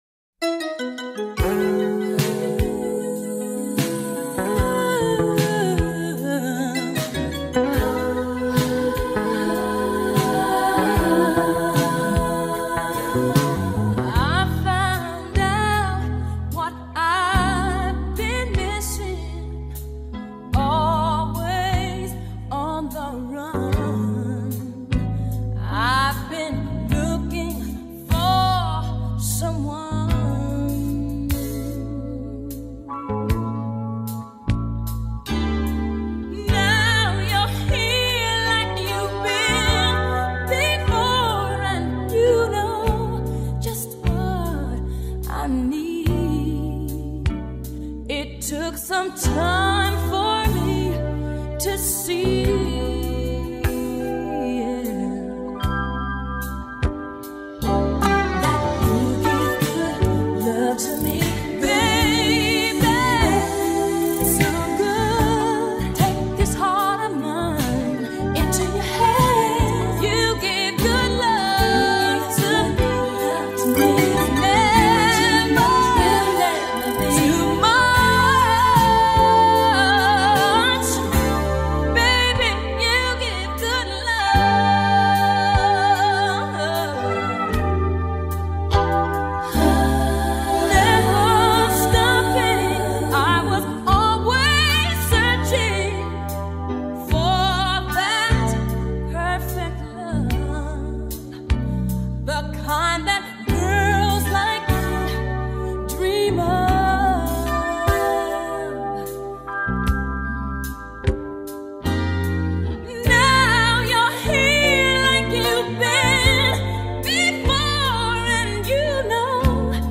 Pop, R&B, Soul